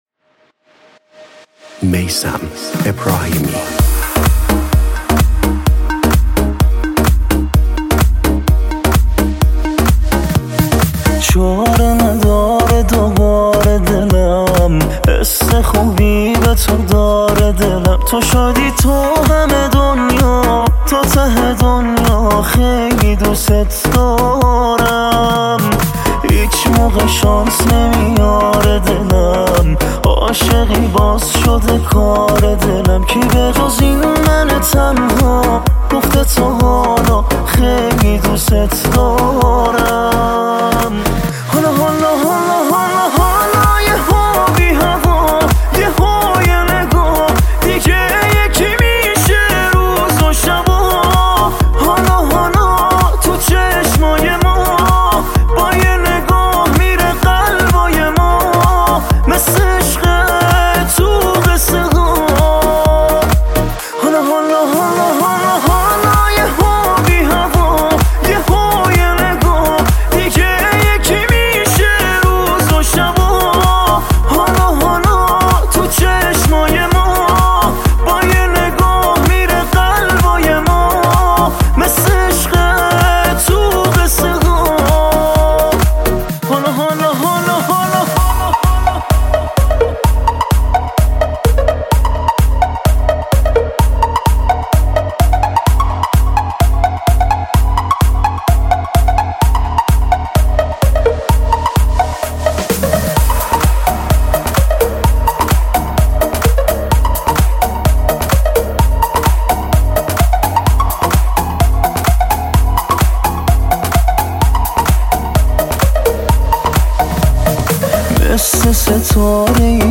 پاپ عاشقانه پاپ